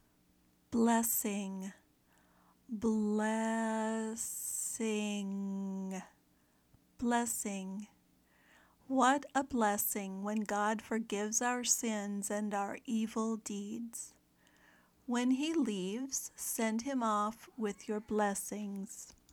/ˈbles ɪŋ/ (noun)